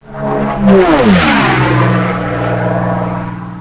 The sound is perhaps equally distinctive. No other aircraft purrs like this one does at full throttle.
• Engine: Merlin-Rolls Royce 12 cylinder with 1590 hp.